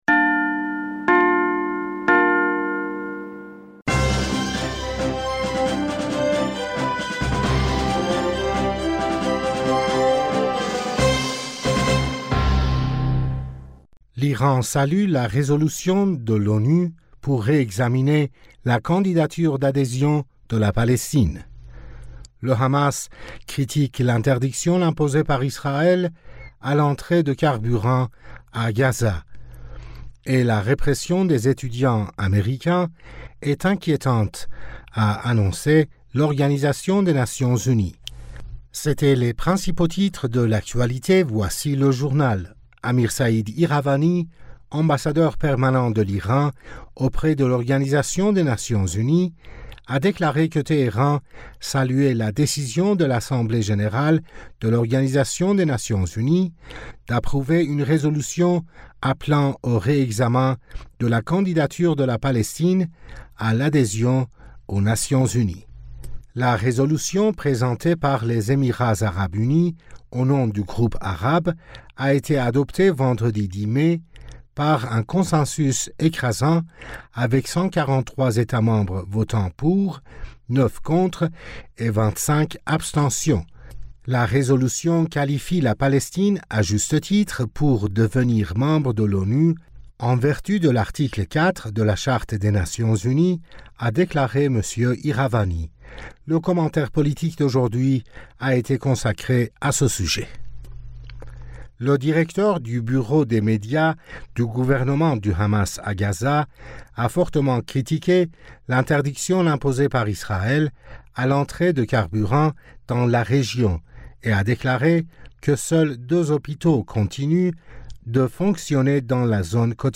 Bulletin d'information du 11 Mai